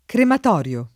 vai all'elenco alfabetico delle voci ingrandisci il carattere 100% rimpicciolisci il carattere stampa invia tramite posta elettronica codividi su Facebook crematorio [ kremat 0 r L o ] agg. e s. m.; pl. m. -ri (alla lat.